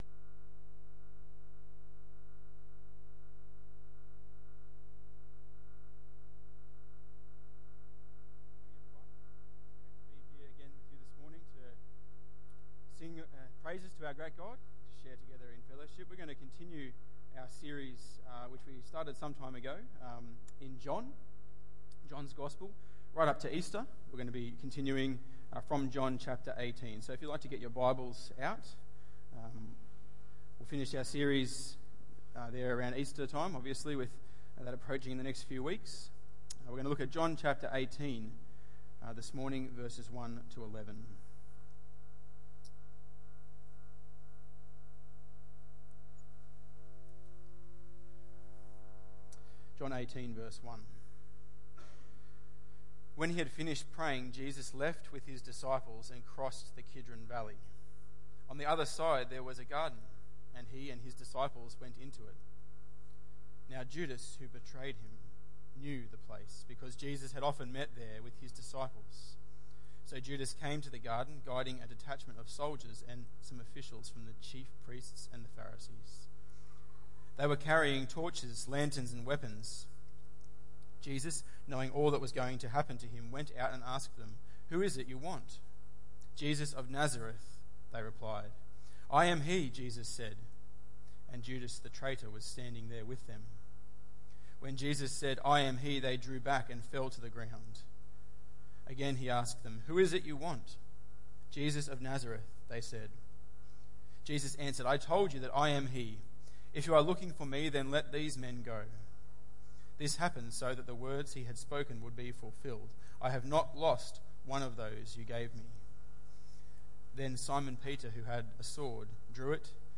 John 18:1-11 Tagged with Sunday Morning